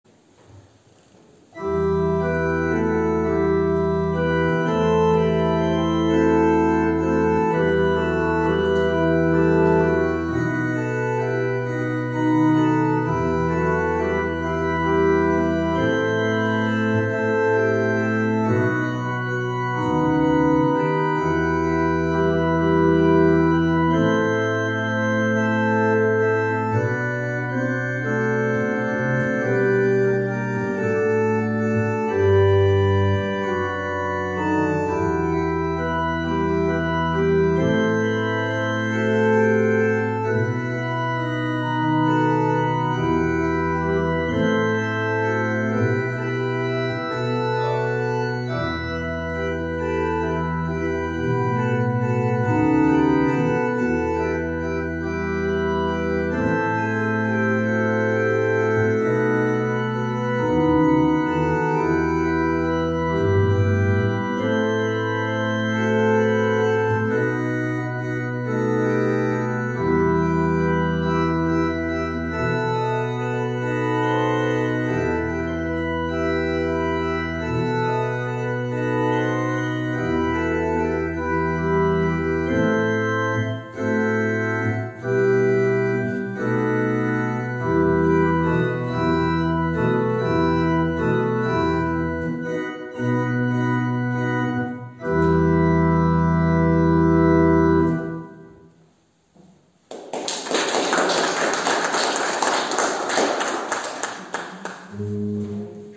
Orgelnachspiel